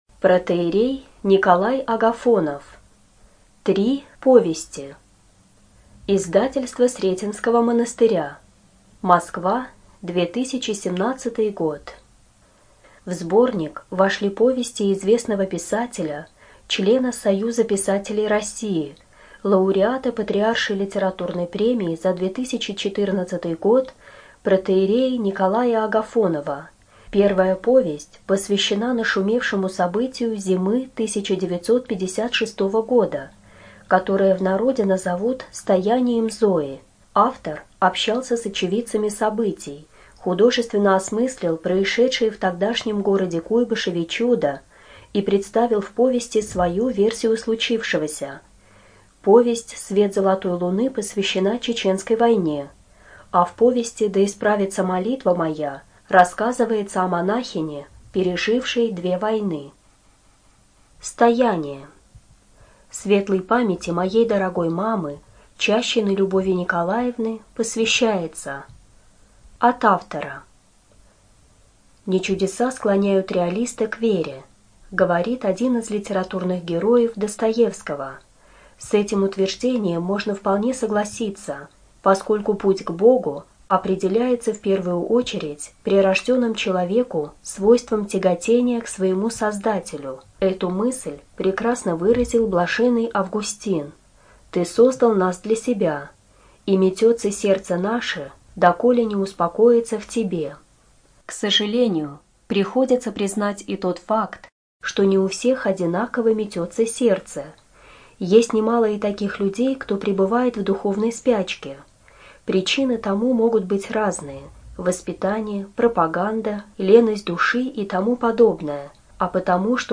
Студия звукозаписиБелгородская областная библиотека для слепых имени Василия Яковлевича Ерошенко